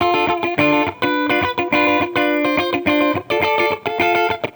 Index of /musicradar/sampled-funk-soul-samples/105bpm/Guitar
SSF_TeleGuitarProc1_105D.wav